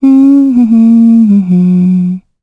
Gremory-Vox_Hum.wav